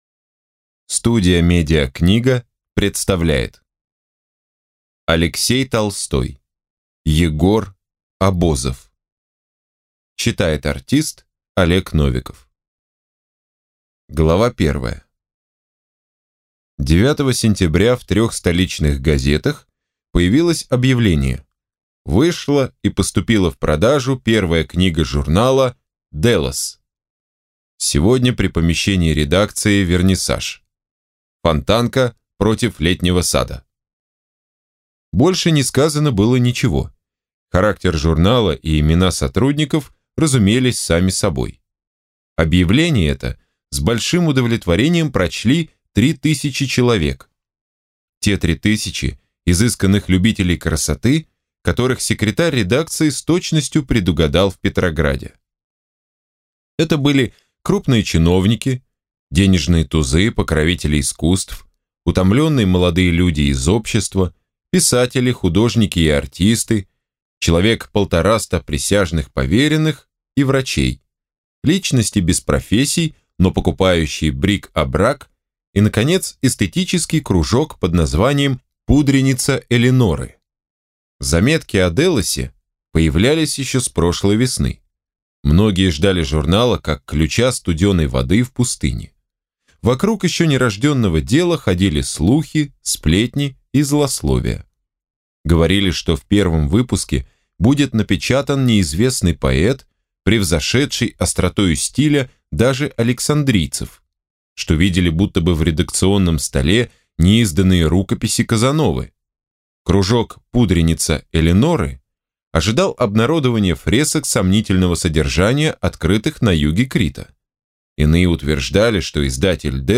Аудиокнига Егор Абозов | Библиотека аудиокниг